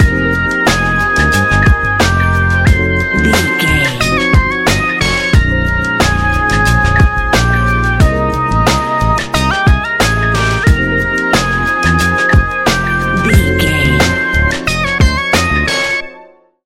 Ionian/Major
chilled
laid back
Lounge
sparse
new age
chilled electronica
ambient
atmospheric
instrumentals